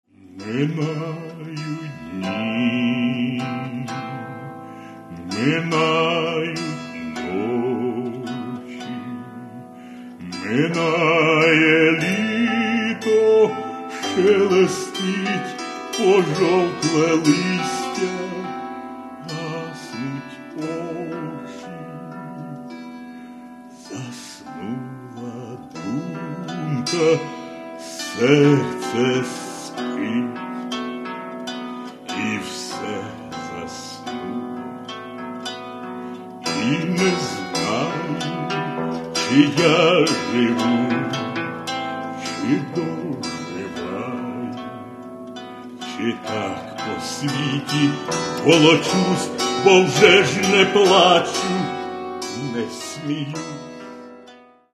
Каталог -> Народная -> Бандура, кобза